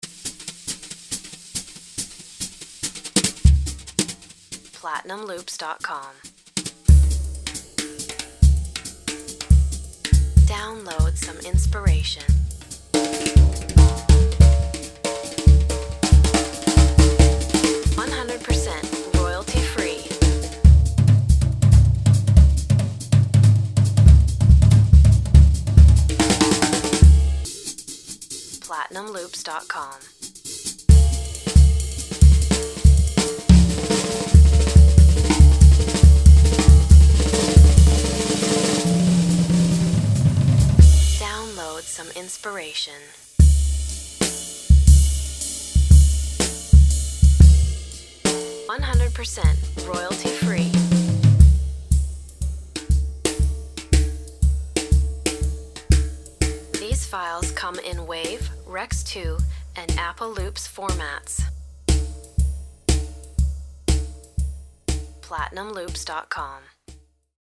Played in a cool and jazzy ‘swing’ style, these loops offer you a wealth of variation by using different drumming techniques as well as different parts of the kit. You’ll get plenty of frenetic drum fills as well as standard grooves that were played using brushes, sticks and rods for that classic Jazz sound.
We’ve even included some epic tom tom grooves which you’ll hear in the demo, these are great for bridge sections when you want to change the dynamics of your song.
Our top class drummers played these live rhythms and blistering fills using hickory drum sticks, steel brushes and rods to give you a wide palette of tone and velocity.
Whether you produce Jazz or simply need a sick drum break, these live acoustic jazz drum loops and fills will inspire.